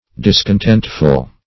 Discontentful \Dis`con*tent"ful\, a. Full of discontent.